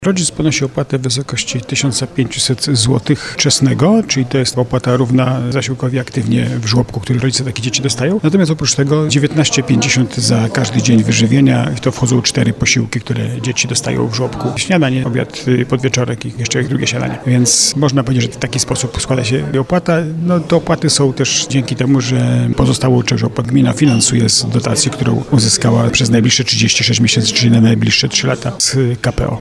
Jak powiedział burmistrz Starego Sącza Jacek Lelek, rodzice mają też konkretne wsparcie finansowe, bo bezpośrednio płacą tylko za wyżywienia.